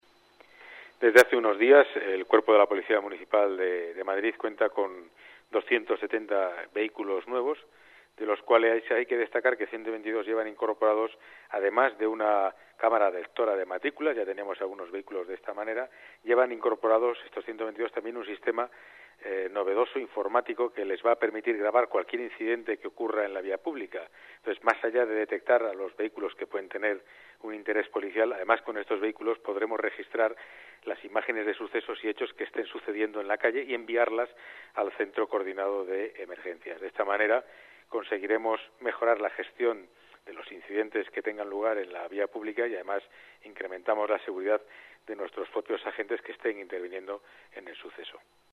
Nueva ventana:Declaraciones de Pedro Calvo, delegado de Seguridad